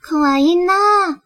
描述：我是美国人，非常不会说日语。
对不起任何发音问题。
Tag: 说话 声音 女孩 女性 声带 日本